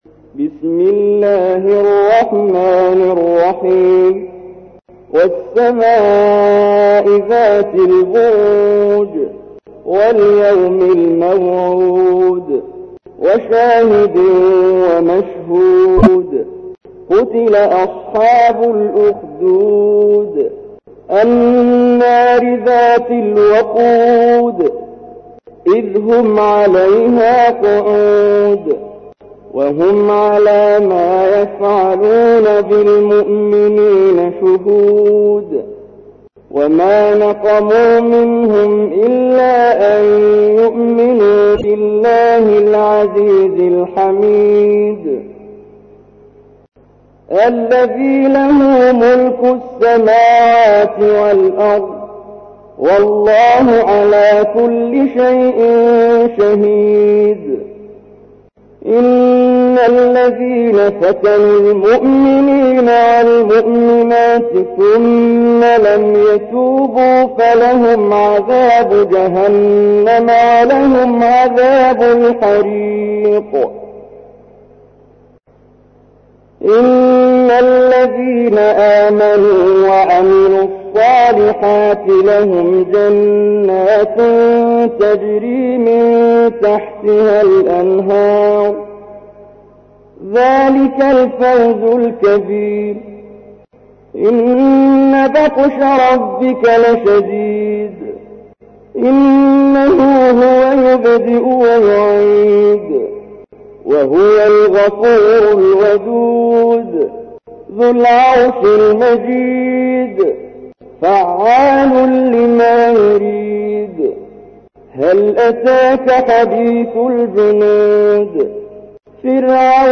تحميل : 85. سورة البروج / القارئ محمد جبريل / القرآن الكريم / موقع يا حسين